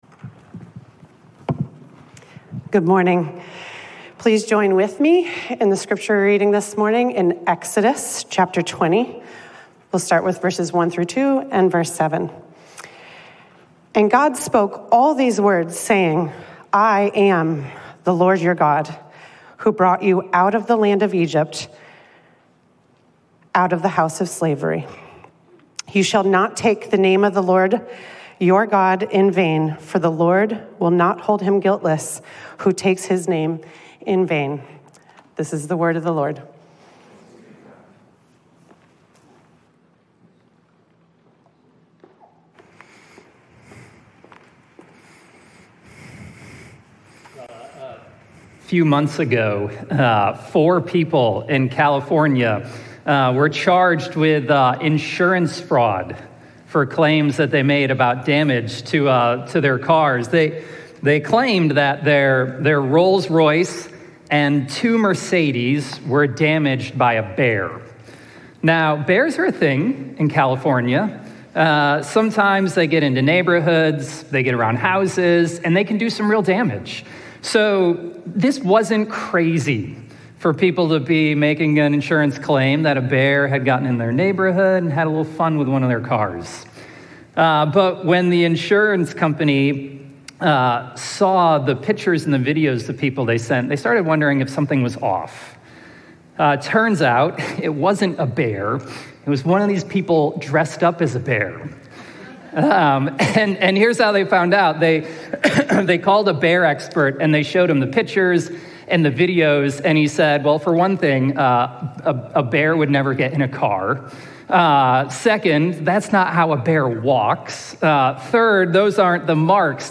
Sermon+-+6.8.25.mp3